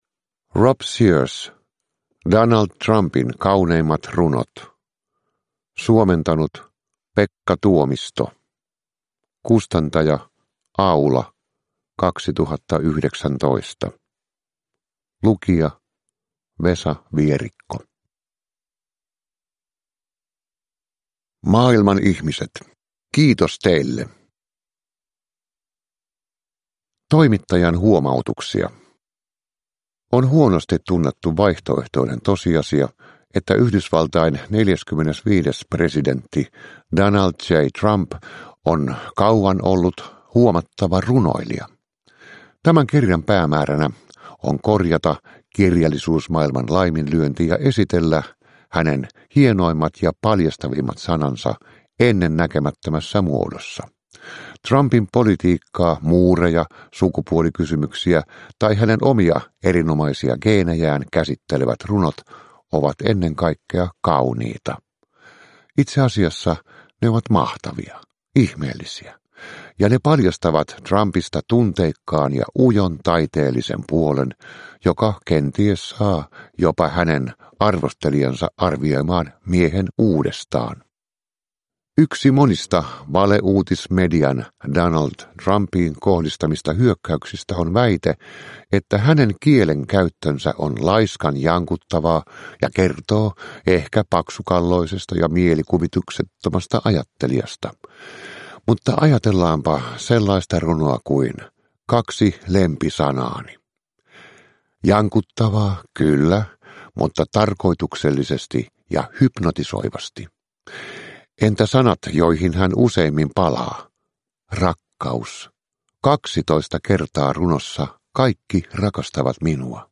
Donald Trumpin kauneimmat runot (ljudbok) av Rob Sears